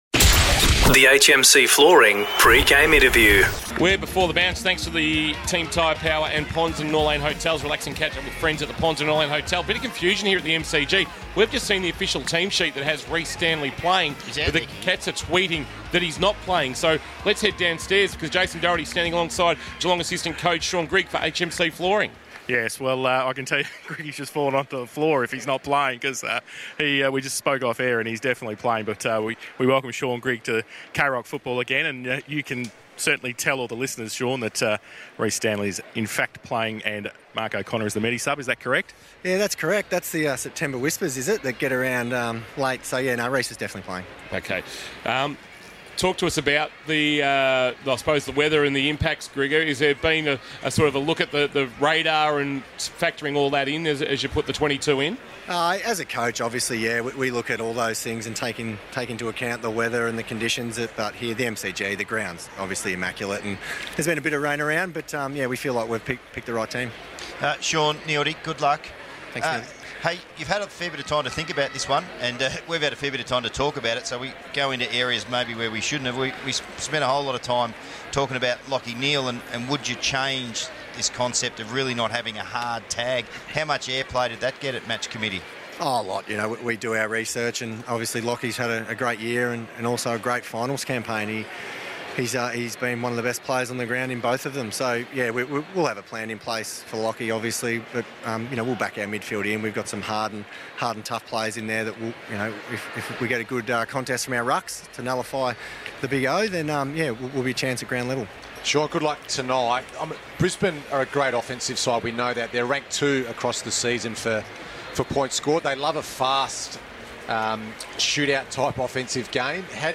2022 – AFL PRELIMINARY FINAL 1 – GEELONG vs. BRISBANE LIONS: Pre-match Interview